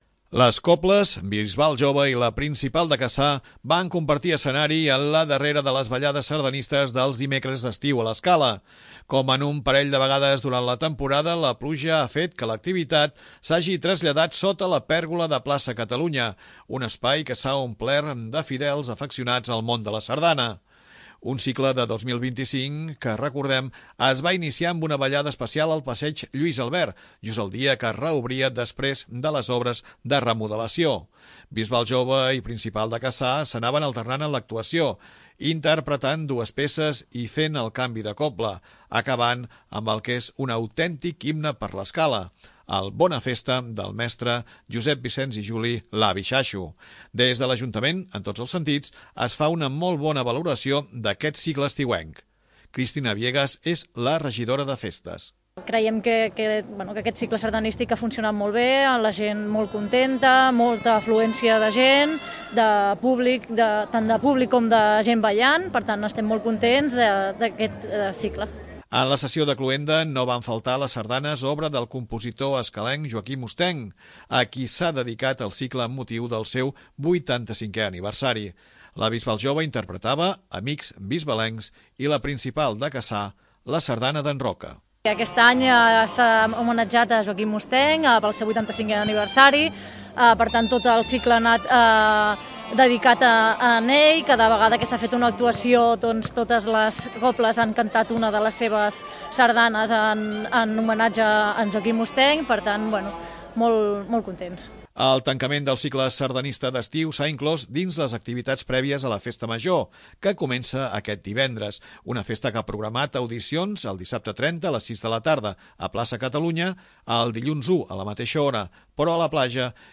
Les cobles Bisbal Jove i La Principal de Cassà van compartir escenari en la darrera de les ballades sardanistes dels dimecres d'estiu a l'Escala.
Com en un parell de vegades durant la temporada la pluja ha fet que l'activitat s'hagi traslladat sota la pèrgola de Plaça Catalunya, un espai que s'ha omplert de fidels afeccionats al món de la sardana.